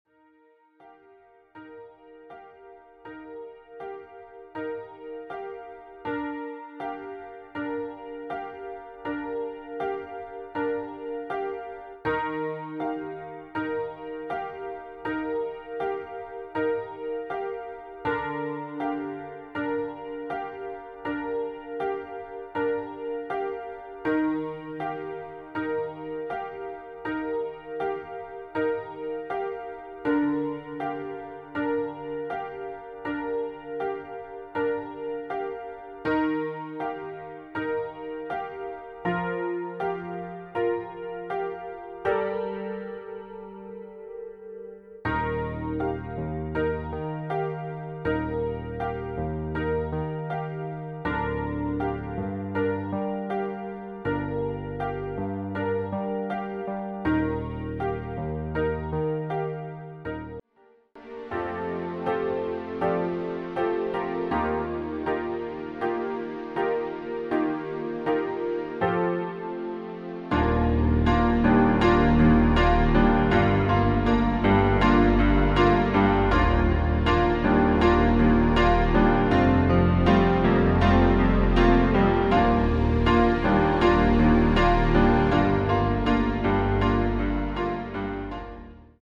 • Tonart: Gm, Am, Hm
• Das Instrumental beinhaltet NICHT die Leadstimme
Klavier / Streicher